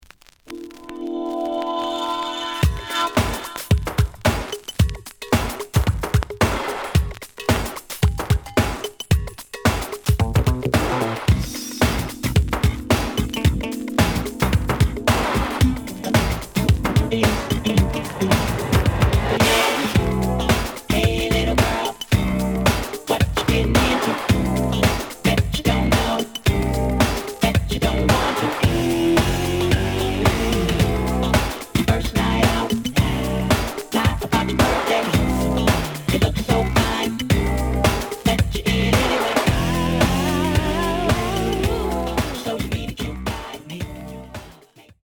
The audio sample is recorded from the actual item.
●Genre: Funk, 80's / 90's Funk
Edge warp.